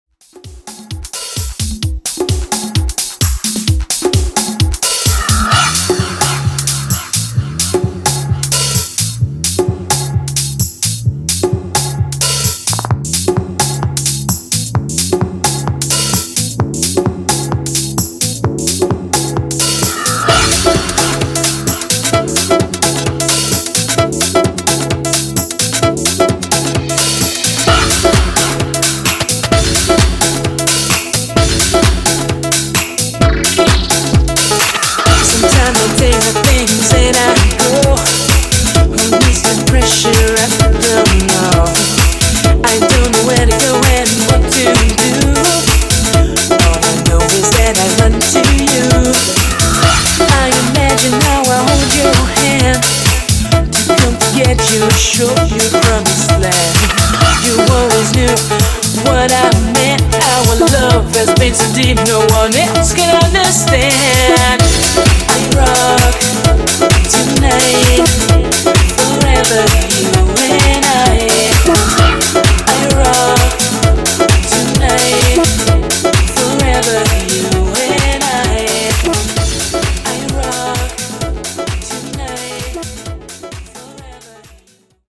DRUM AND BASS , FUTURE HOUSE , MASHUPS